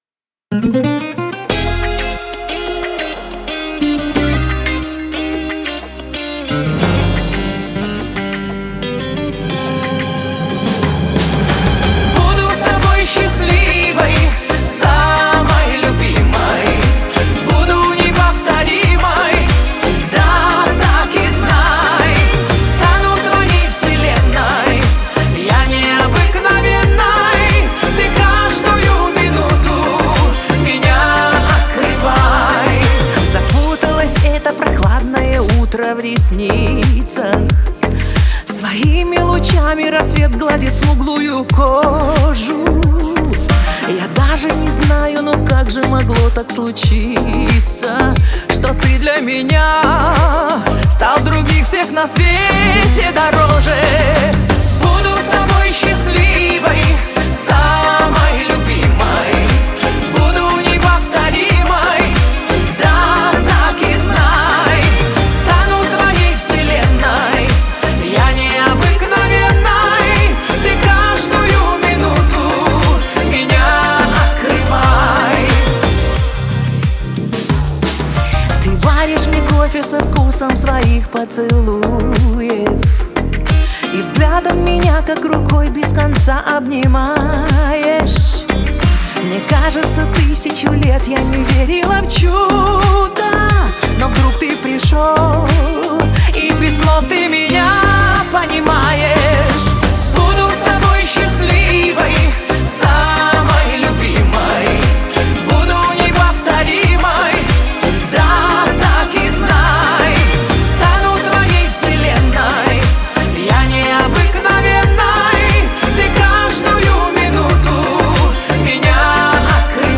это яркий пример поп-музыки с элементами романса.
Особенностью исполнения является мощный вокал